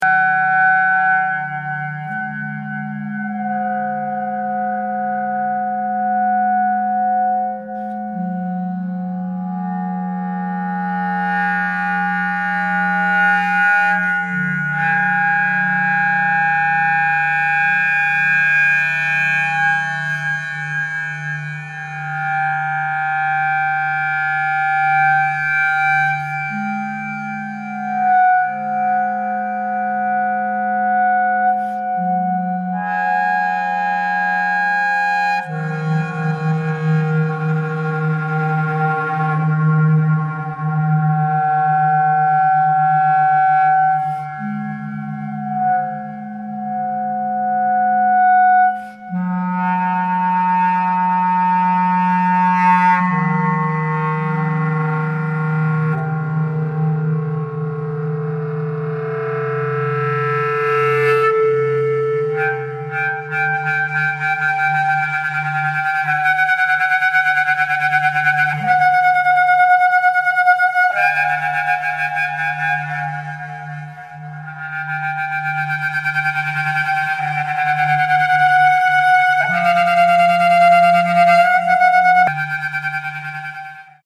Inspired by natural processes and acoustic phenomena
flute
percussion
clarinet